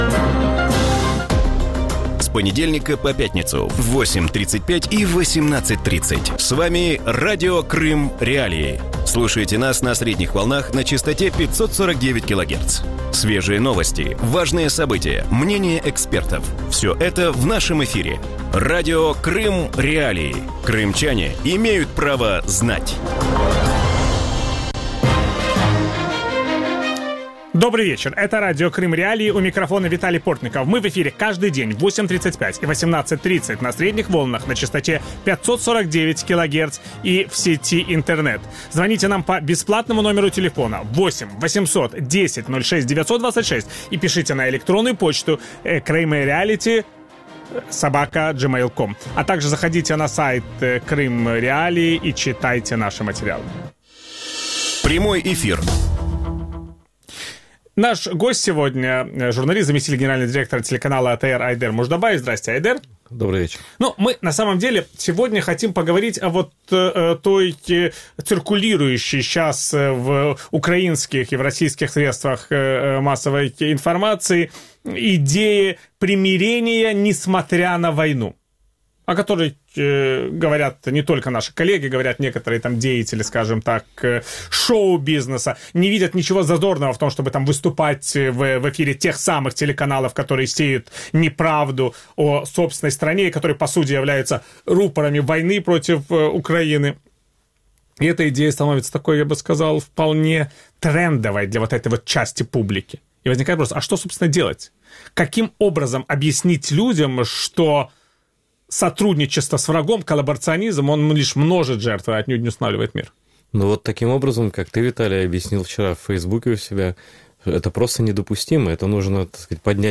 В вечернем эфире Радио Крым.Реалии обсуждают, как изменились отношения между россиянами и украинцами более чем за два года российской агрессии и реально ли примирение между народами.
Ведущий: Виталий Портников.